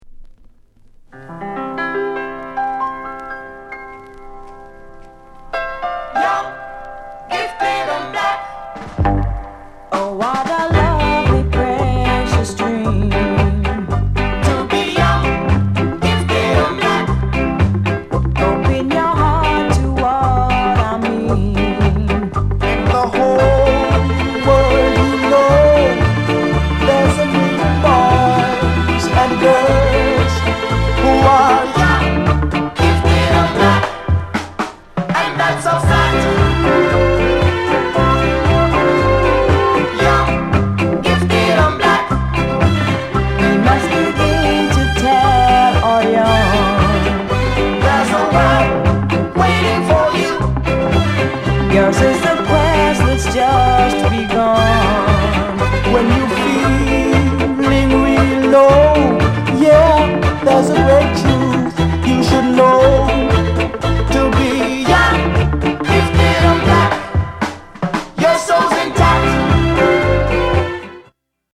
SOUND CONDITION EX-